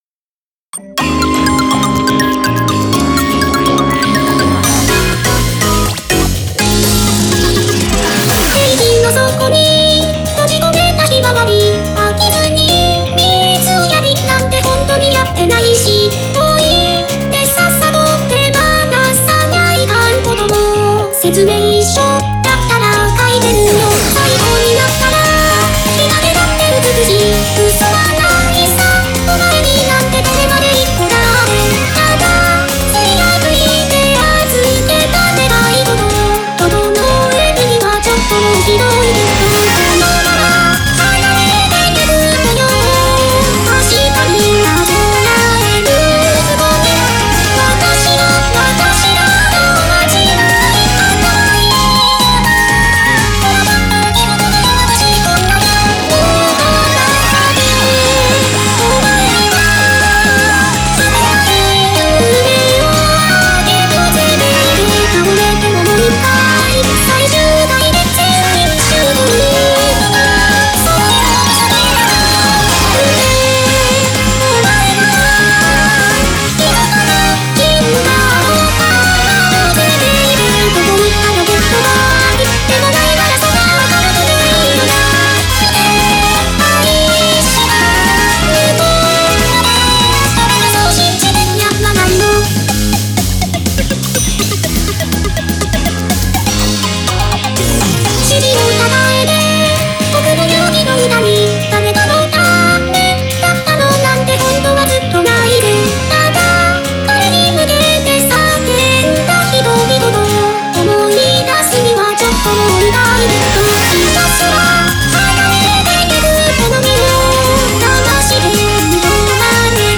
ここにはVOCALOIDの曲を置いていきます。